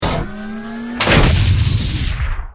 battlesuit_handcannon.ogg